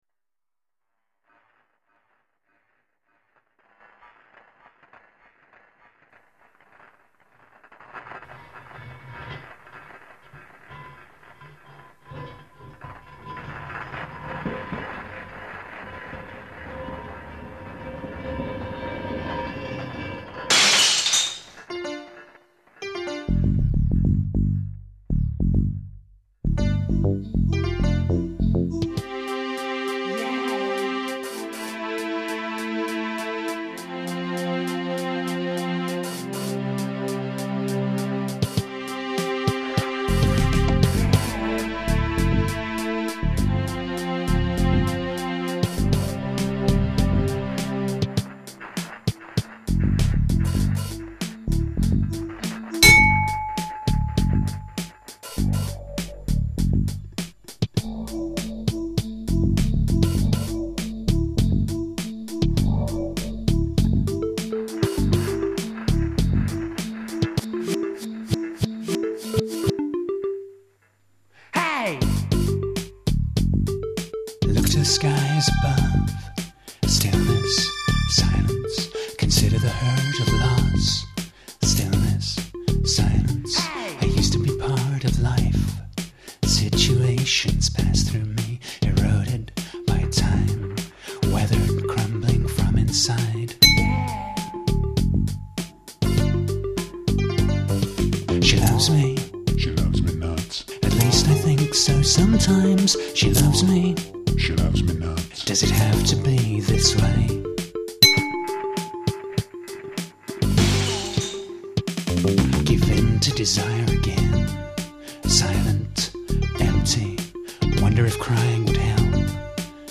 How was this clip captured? These songs are all 128 Kbit/s stereo MP3s.